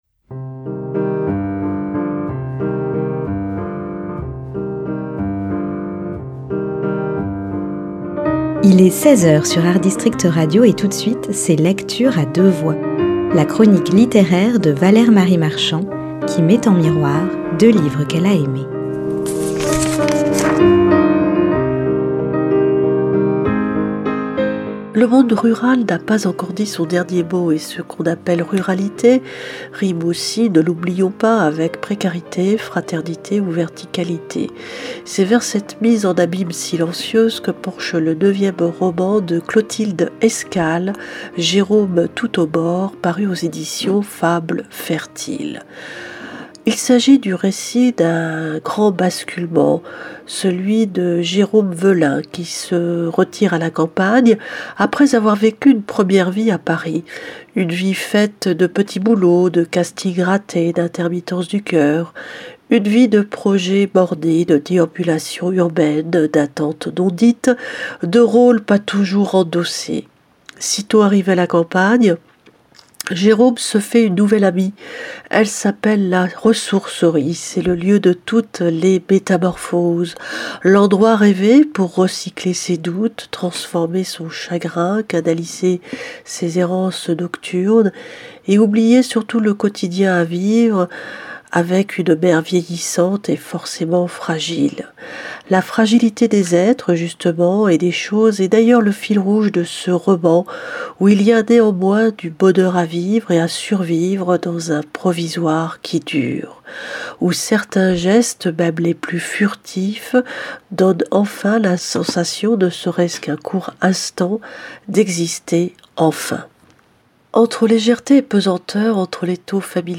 LECTURE A DEUX VOIX, mardi et vendredi à 10h et 16h.